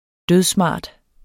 Udtale [ ˈdøðˈsmɑˀd ]